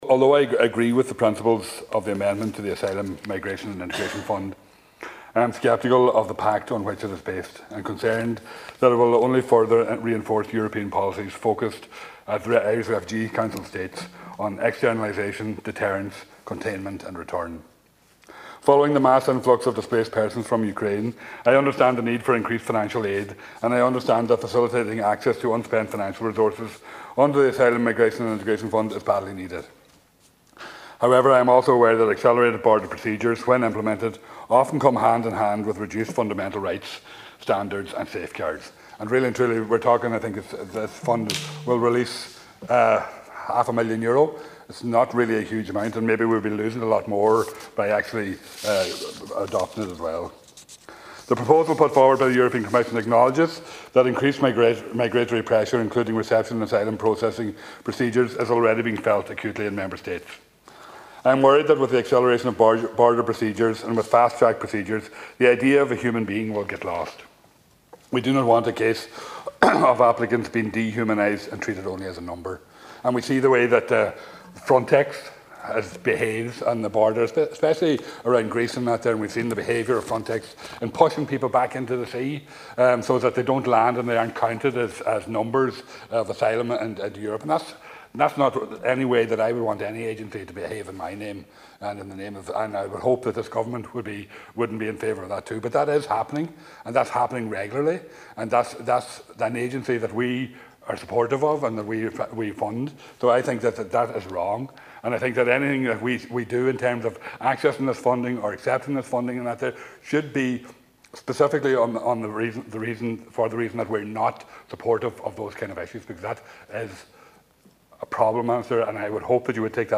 Deputy Thomas Pringle was speaking in the Dail and says while he agrees with the principles of the amendment to the Asylum, Migration and Integration Fund he is concerned that it will only further reinforce European policies.